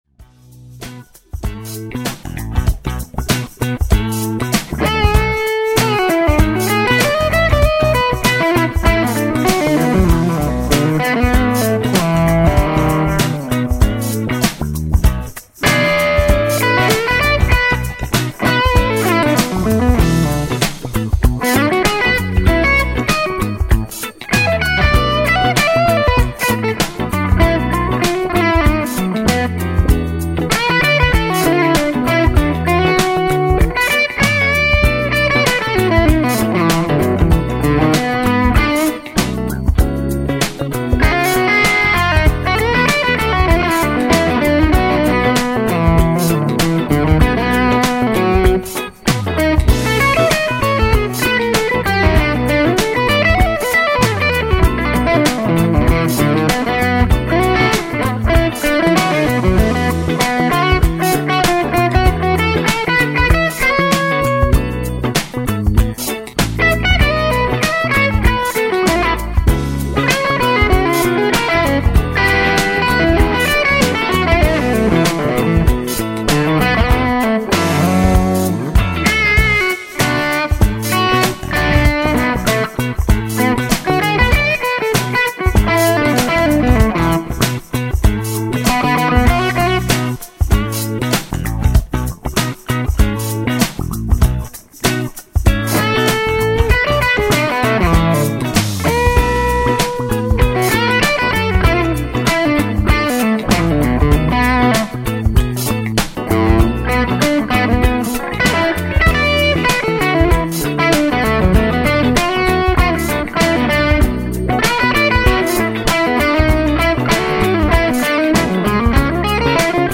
All Red White and Blue speakers and Baker RF on bridge hum. SM57 mic.
HRM mode with PAB on, crazy low gain pre-OD network (need to run boost as it is low gain, also readjust stack to compensate for PAB mode)